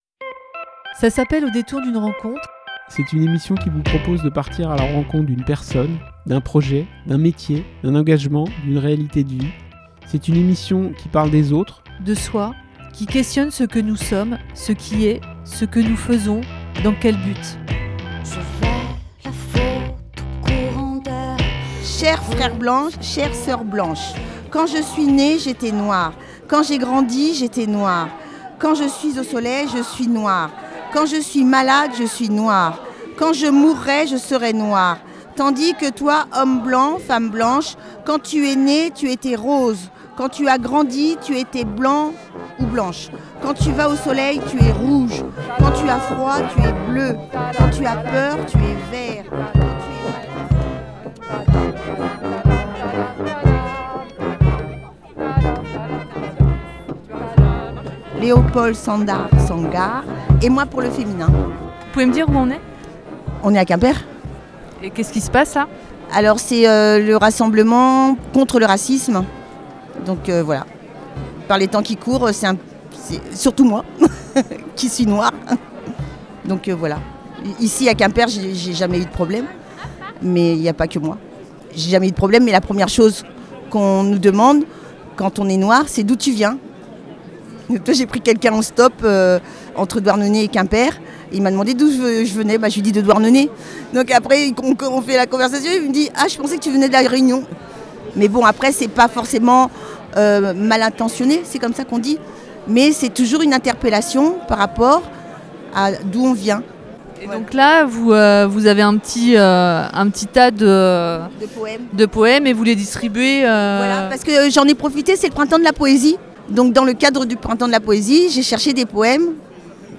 Ecouter le podcast Télécharger le podcast Accueil Actus Au détour d'une rencontre Au détour d'une manif Au détour d'une manif Publié le 03/04/2025 Donner à entendre les sons et les bruits d'une manif contre le racisme à Quimper sous la pluie : des slogans, des cris, des poèmes, des indignations, des chants, des étonnements, des cuivres, des coups de colère, des grandes vérités, des joies, des hésitations, des satisfactions, des rires, des rêves et des pas qui dansent...